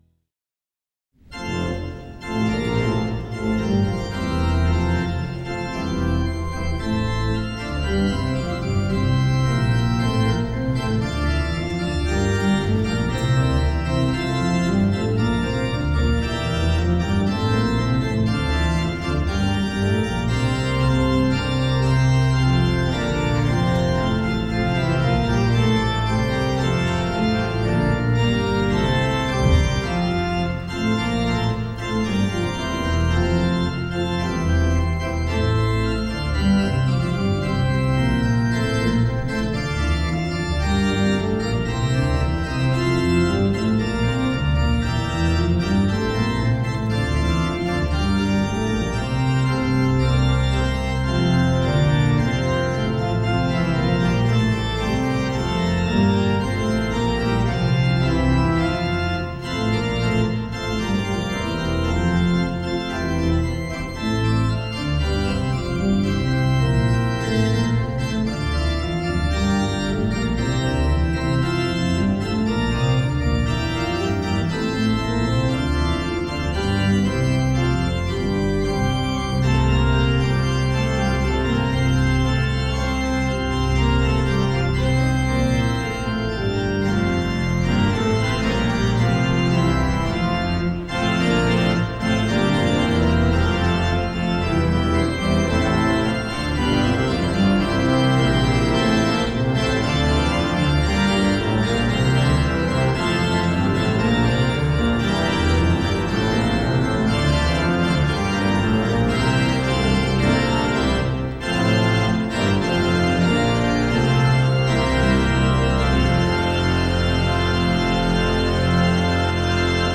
St. Paul's Des Peres Bible Study — The Acts of the Apostles
Hear the Bible Study from St. Paul's Lutheran Church in Des Peres, MO, from February 22, 2026.
Join the pastors and people of St. Paul’s Lutheran Church in Des Peres, MO, for weekly Bible study on Sunday mornings.